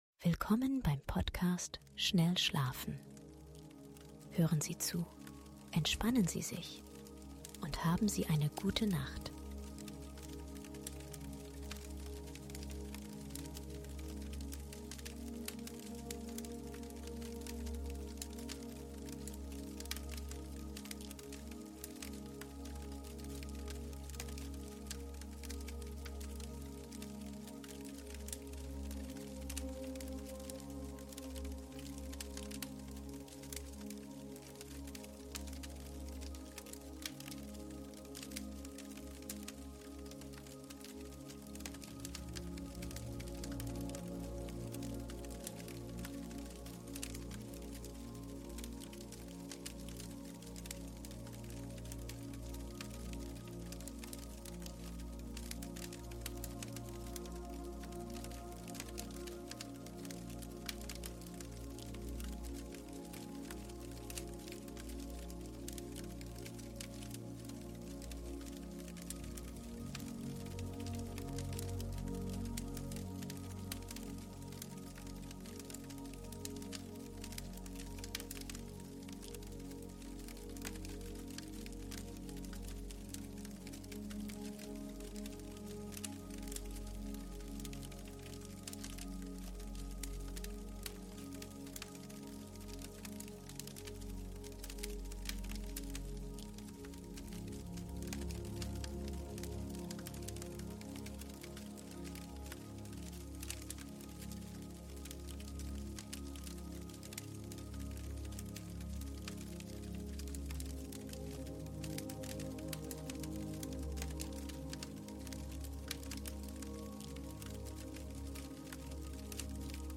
SCHLAFEN Sie zum Rhythmus des FEUERS und Sanfter MUSIK, für Tiefen RUHE